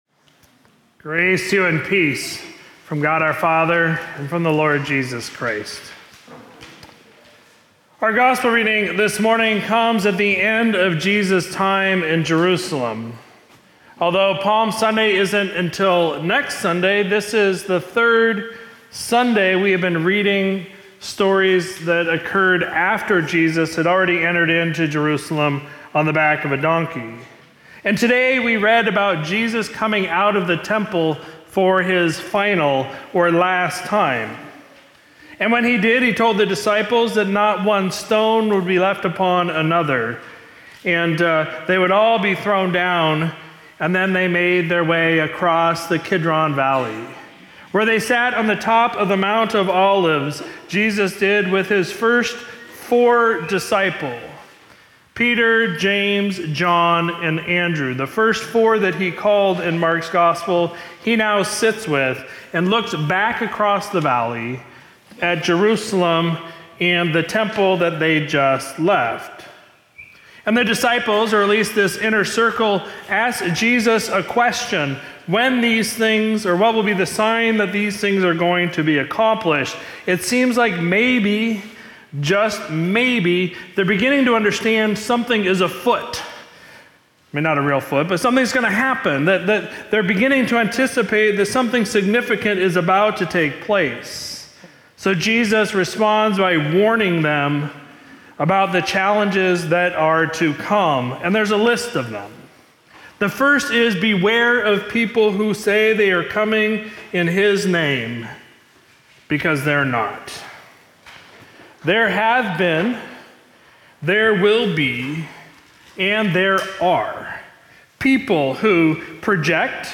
Sermon from Sunday, March 17, 2024